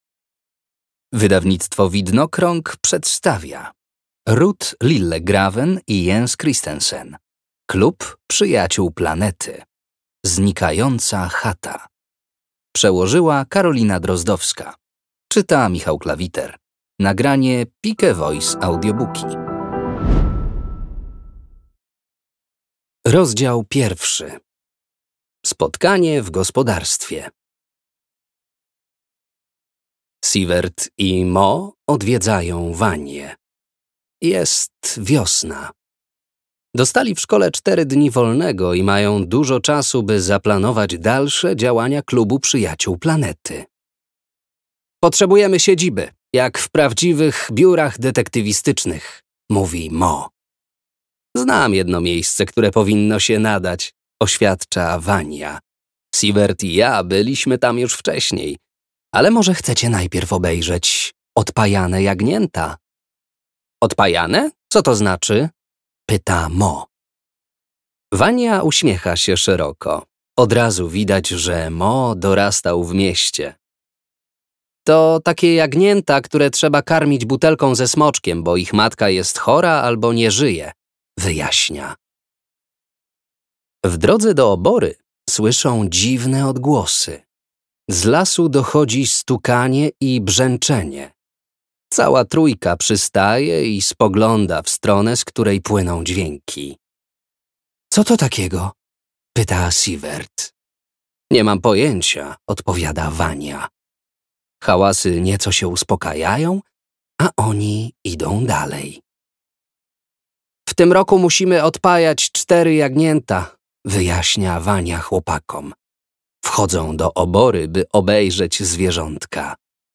Klub Przyjaciół Planety. Znikająca chata - Ruth Lillegraven - audiobook